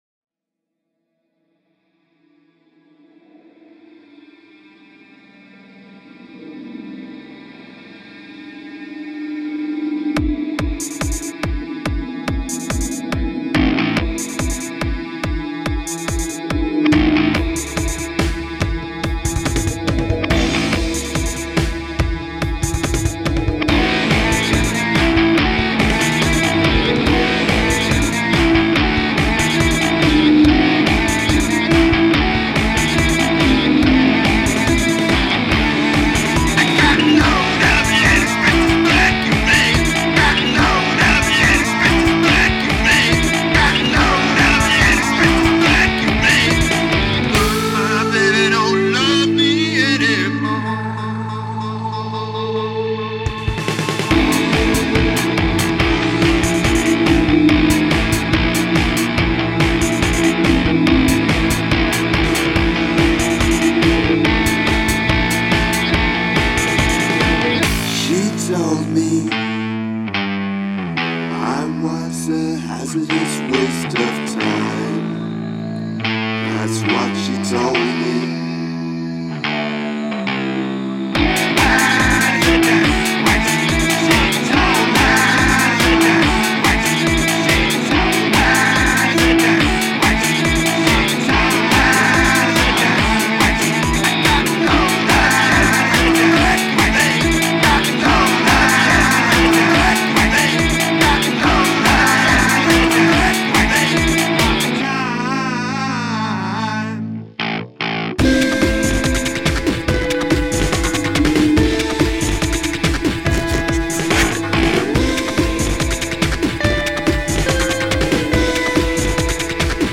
Beatbox